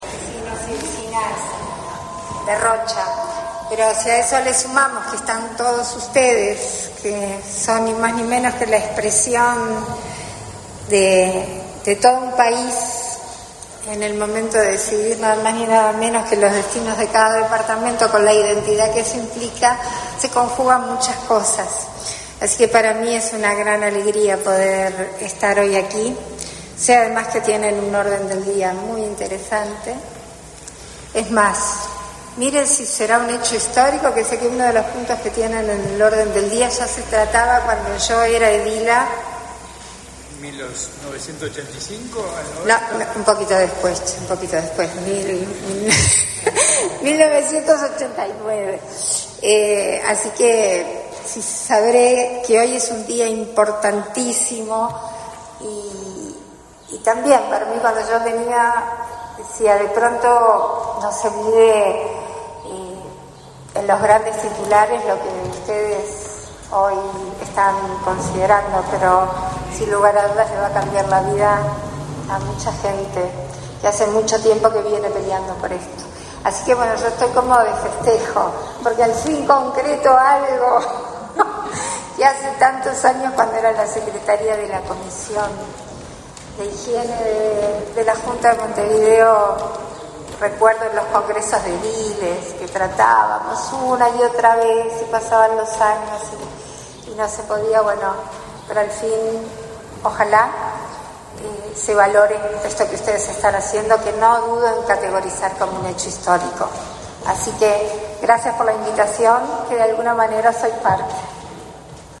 Palabras de la presidenta de la República en ejercicio, Beatriz Argimón
La presidenta de la República en ejercicio, Beatriz Argimón, participó, ese 23 de noviembre, en la reunión del Congreso de Intendentes en Rocha.